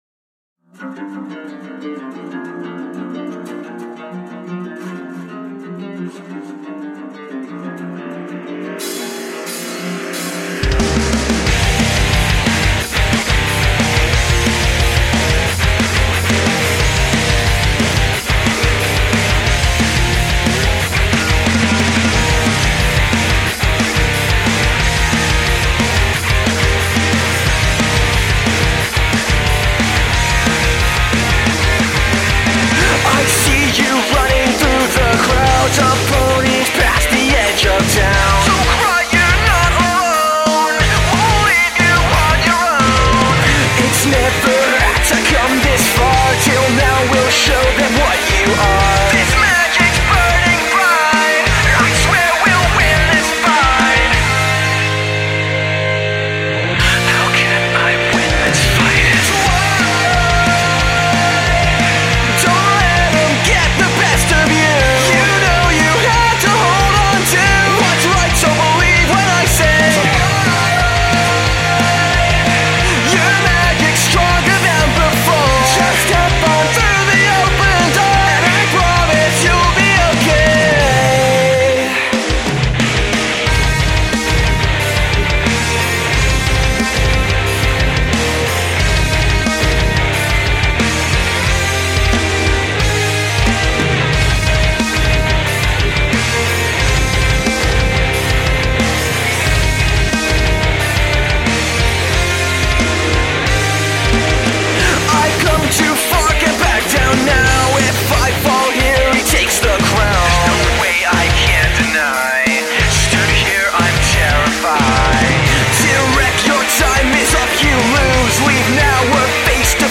female vocals